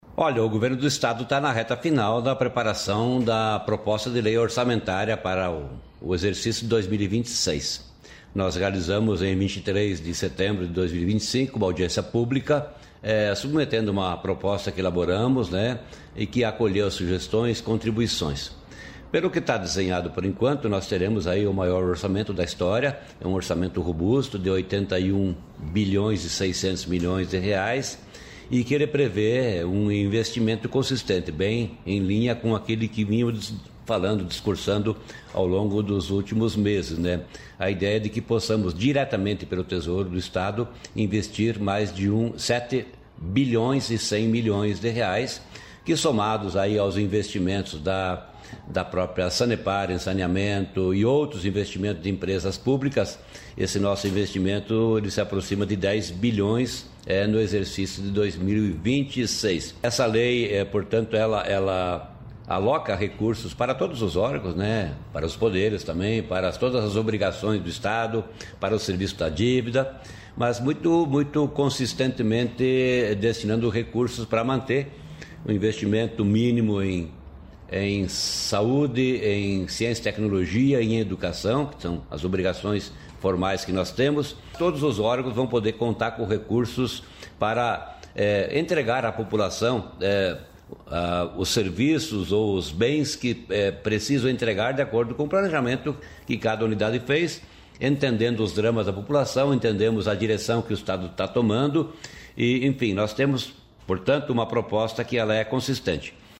Sonora do secretário estadual da Fazenda, Norberto Ortigara, sobre recorde de orçamento